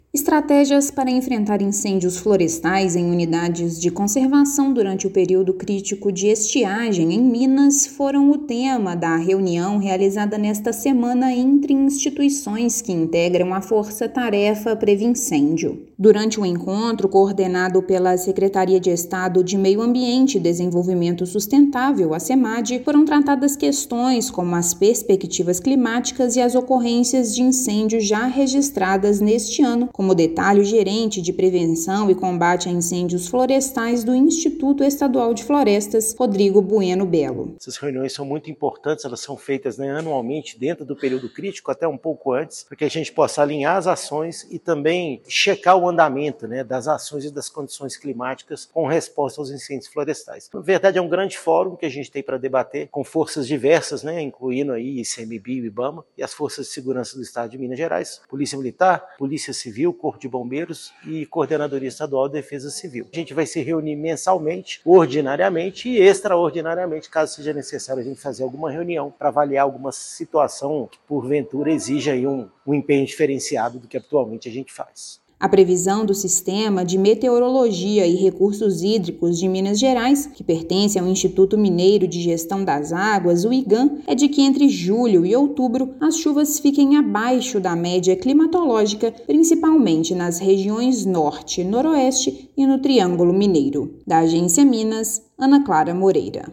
Instituições debateram perspectivas climáticas para este trimestre e o balanço de ocorrências já registradas em 2024. Ouça matéria de rádio.